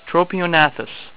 Pronunciation Key
TRO-pe-o-NA-thus